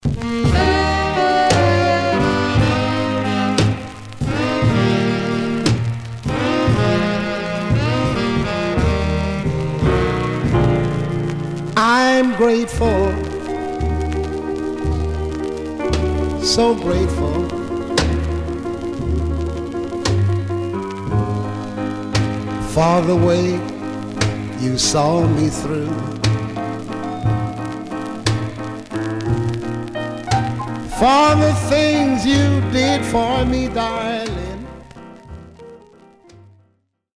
Some surface noise/wear
Stereo/mono Mono
Rythm and Blues